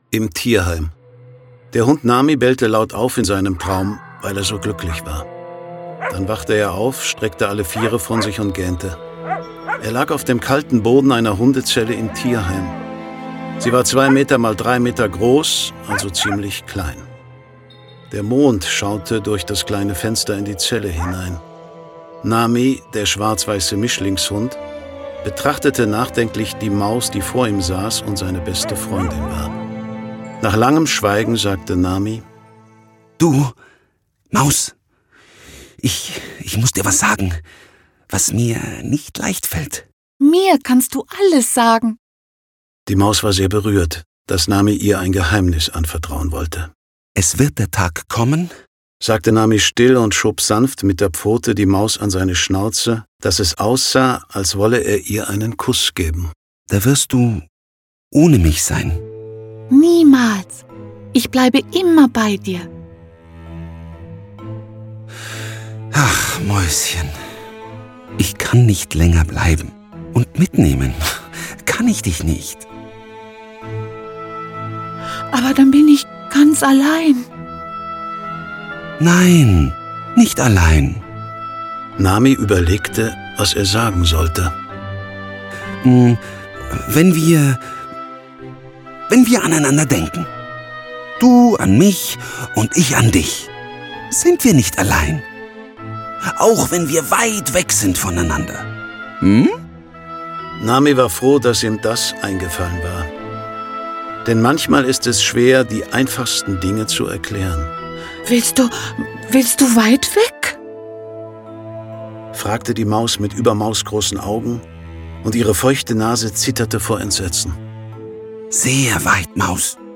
Bis Weihnachten ein Zuhause - Hörbuch